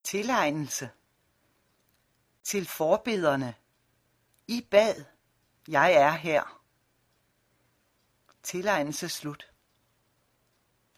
Barmhjertighedens Pris (lydbog)